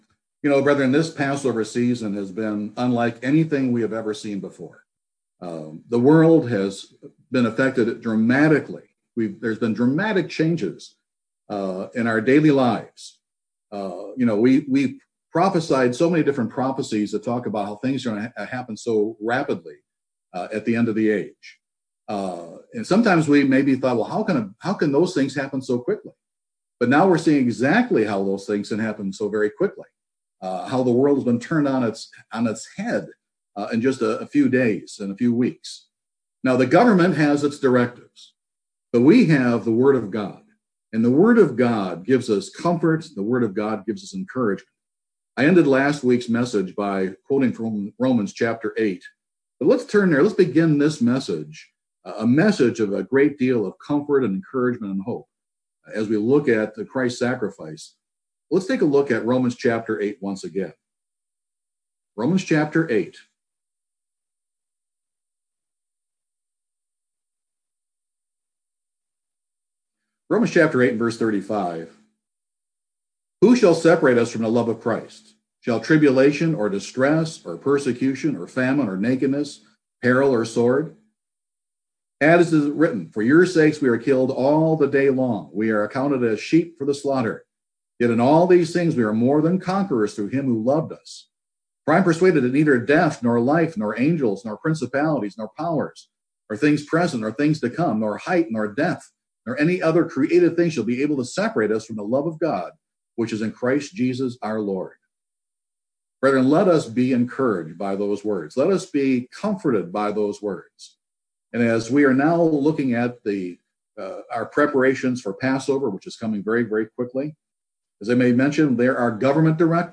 This sermon will examine our understanding of the significance of Christ's shed blood.